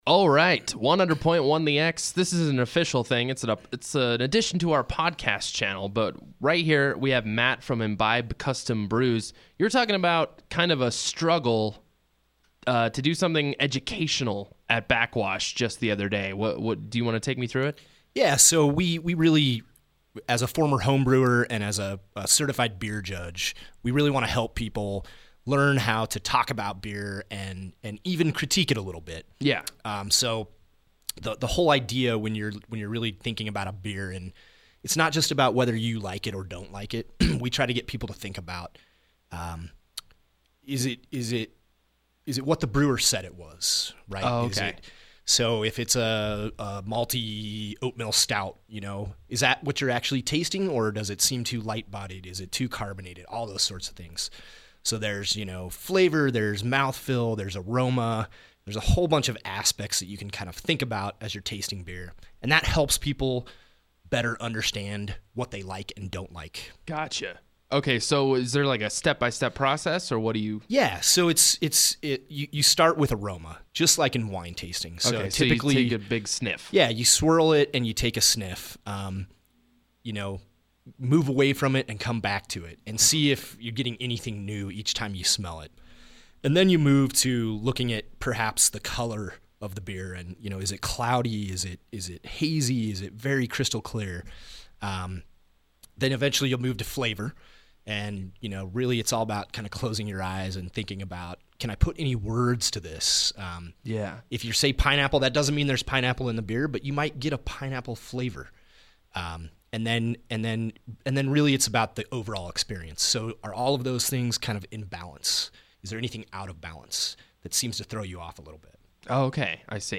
Extended Interview - Imbib Custom Brewing